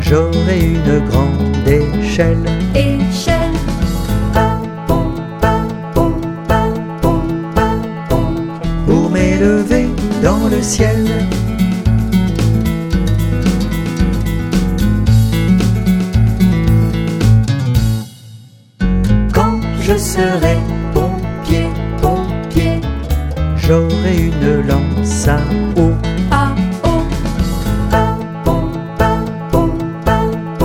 Musicien. Ens. voc. & instr.